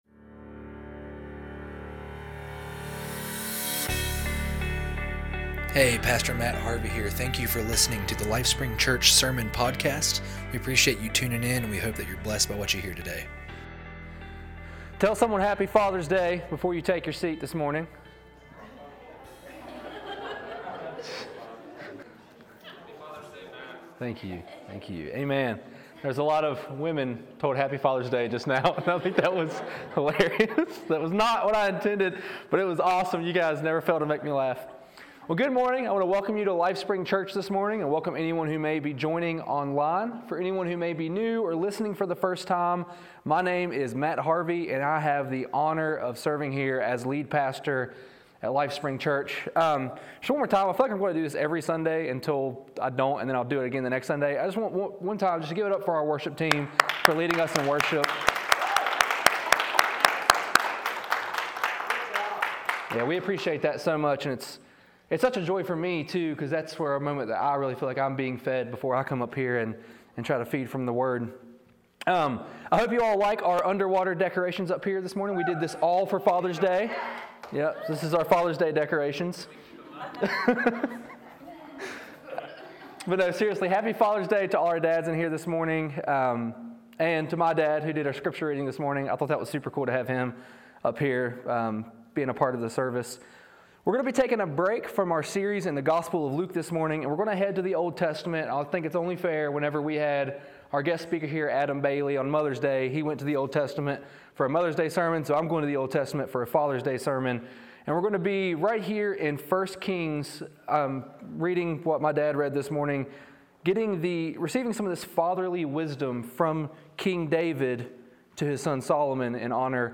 Sermons | LifeSpring Church